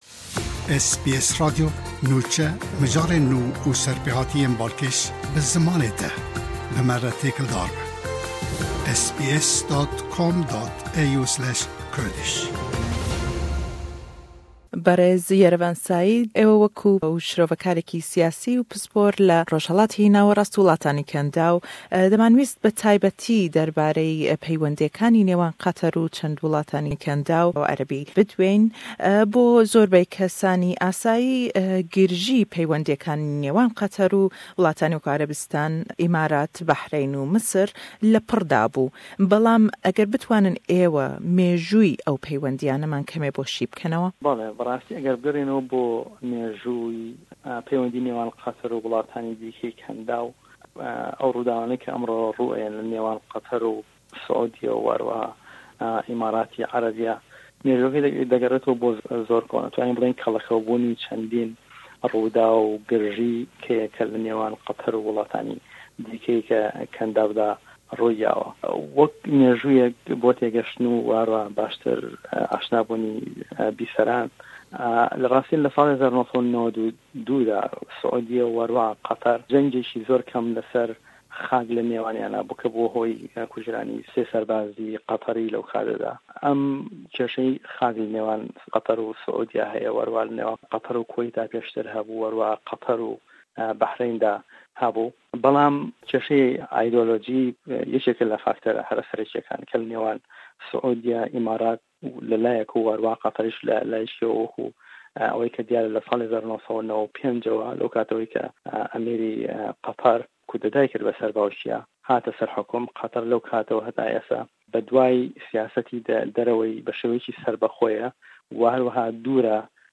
Qeyran le gell willatî Qeter û çwar willatî Araebî dîke berdewame, ke ew willatane dellên abllûqekan le ser Qeter berdewam debêt pash ret kirdinewey mercekaniyan. Le em lêdwane da le gell Shirovekarî Siyasî-pispor le Rojhellatî Nawerast û willatanî Kendaw